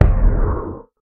Minecraft Version Minecraft Version snapshot Latest Release | Latest Snapshot snapshot / assets / minecraft / sounds / mob / guardian / elder_hit4.ogg Compare With Compare With Latest Release | Latest Snapshot
elder_hit4.ogg